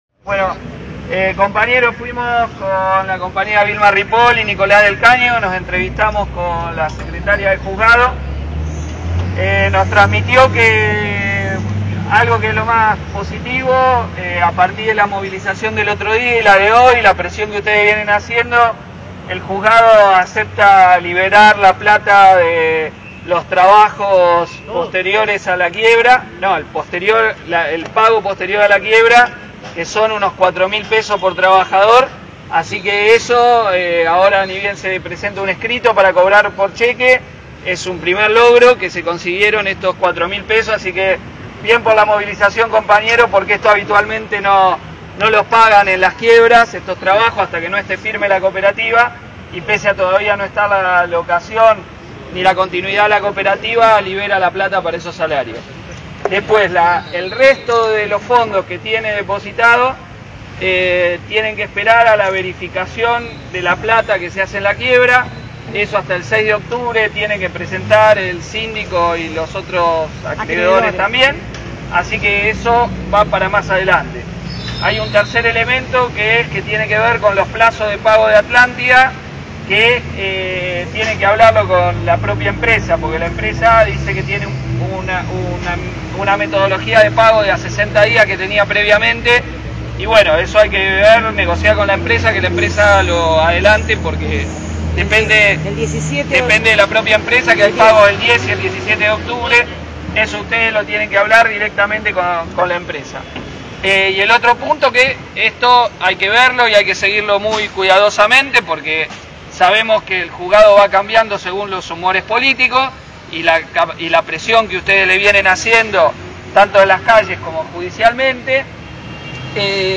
Testimonio de Christian Castillo (diputado bonaerense, PTS-FIT)